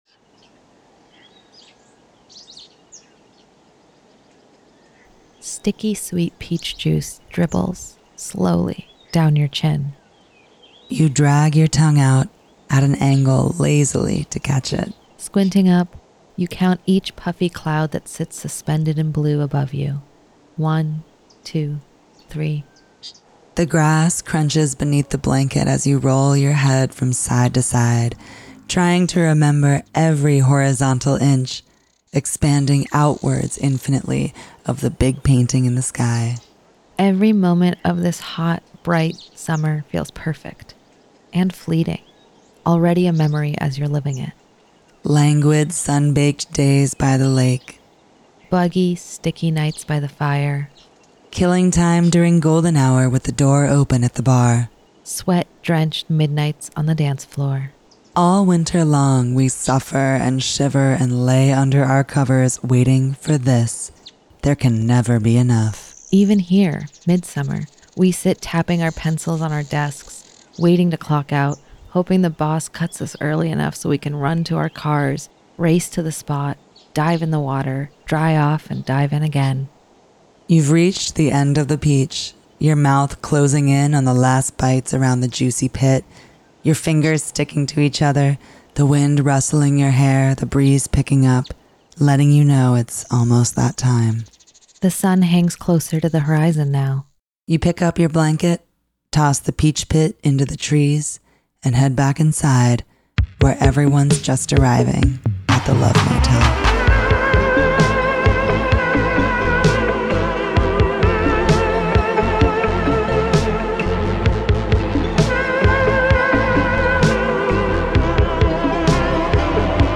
The Love Motel is a monthly radio romance talk show with love songs, relationship advice, and personals for all the lovers in the upper Hudson Valley.